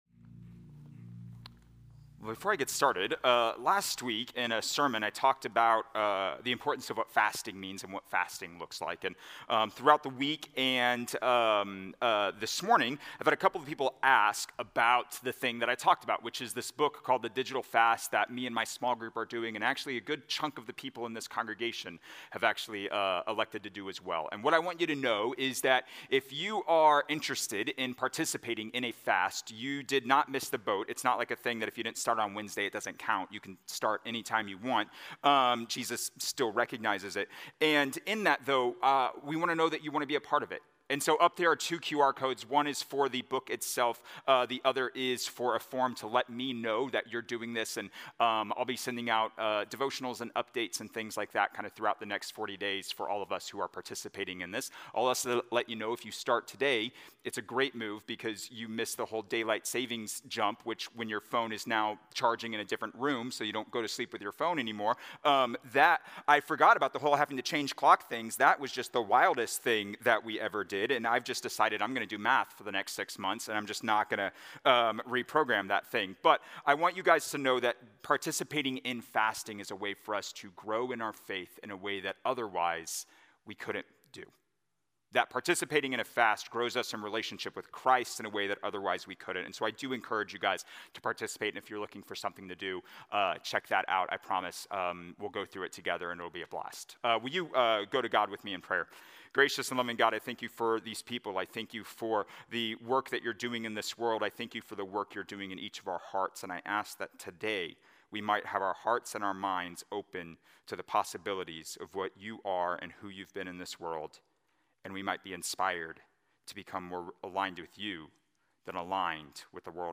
A message from the series "Jesus Parables."